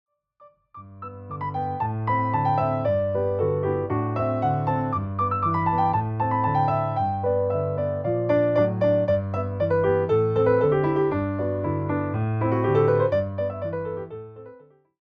” where bright, dancing passages evoke childhood excitement.